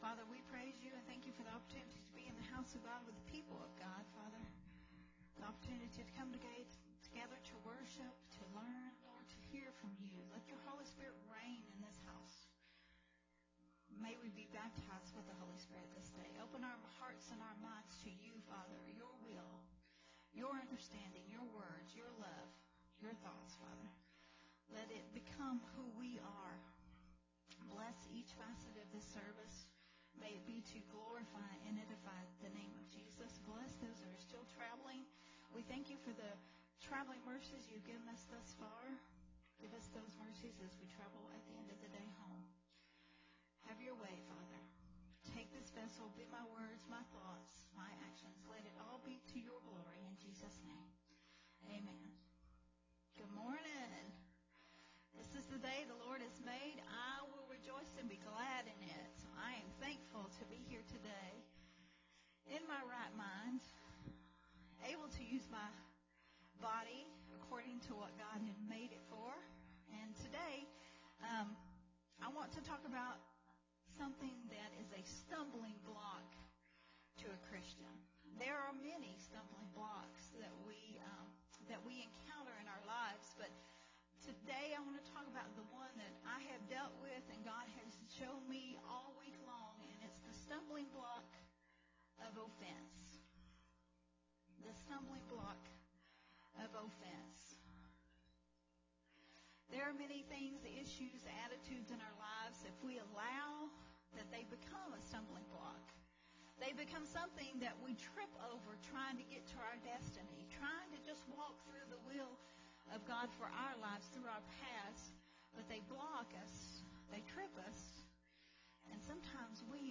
recorded at Unity Worship Center